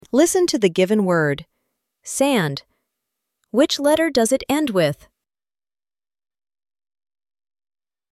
Listen to the given word.